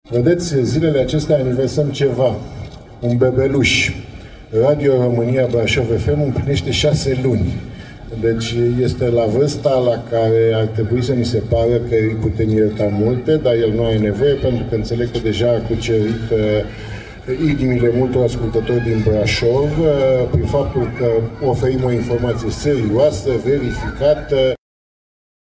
Revine președintele Societății Române de Radiodifuziune, Gerogică Severin: